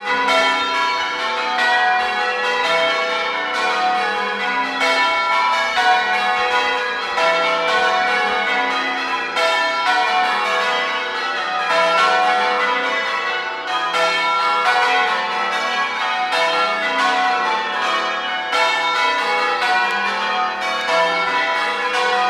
Bumped into a church just in time for 15+ minutes of the most out of tune bells I’ve ever listened to.